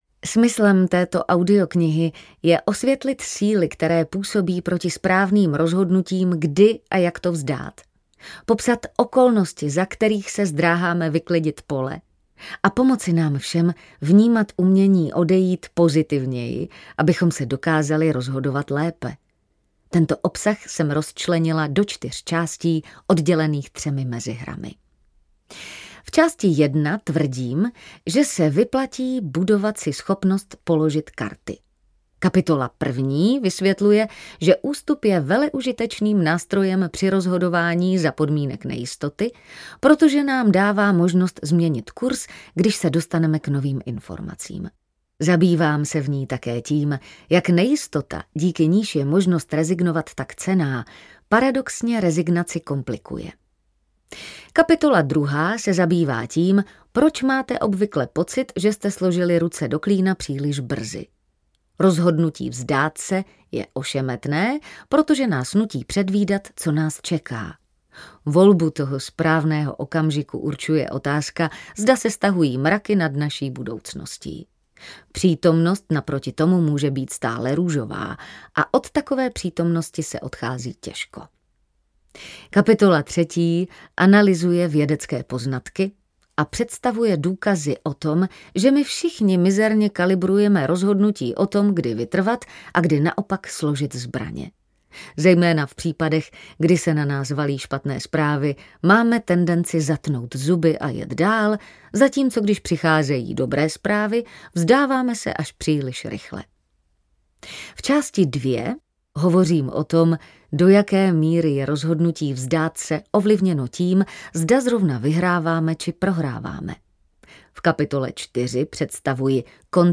Umění skončit audiokniha
Ukázka z knihy